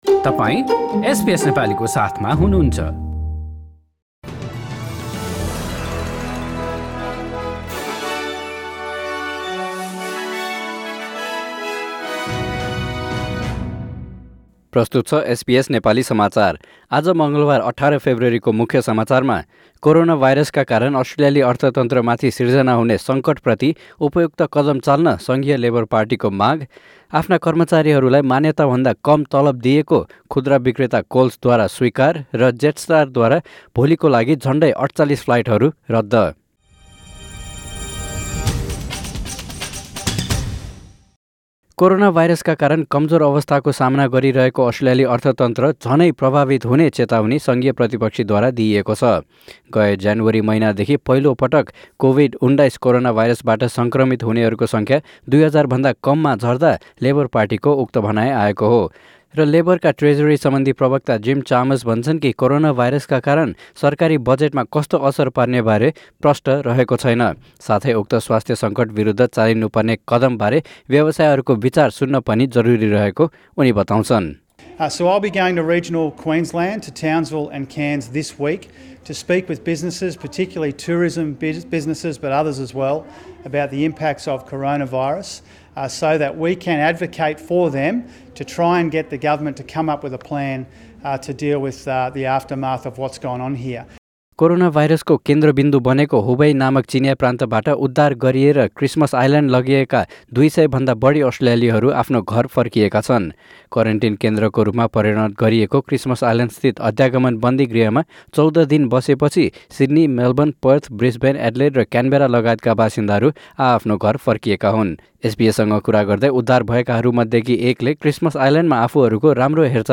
एसबीएस नेपाली अस्ट्रेलिया समाचार: मङ्गलवार १८ फेब्रुअरी २०२०